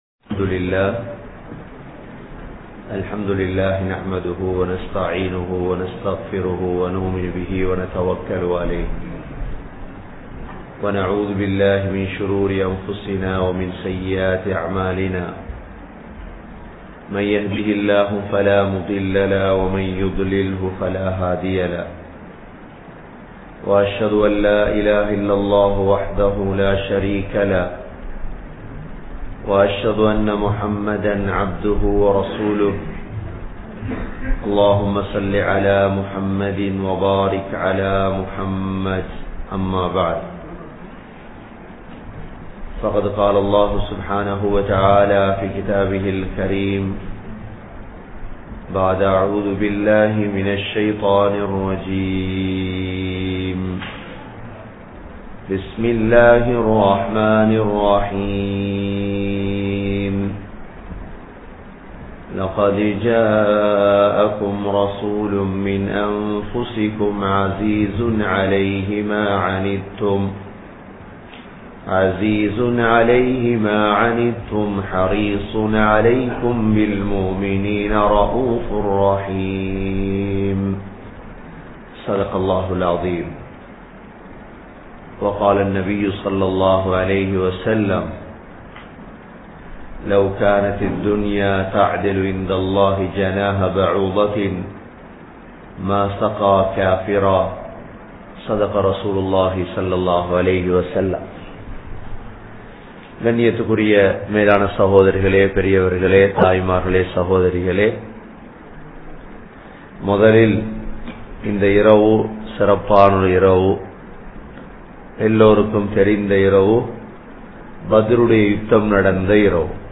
Seerah Part 03 | Audio Bayans | All Ceylon Muslim Youth Community | Addalaichenai